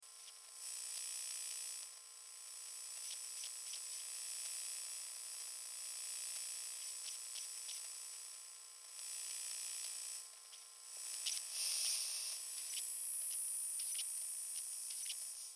Звуки прибора ночного видения
Звук при наблюдении за врагами вдаль через ПНВ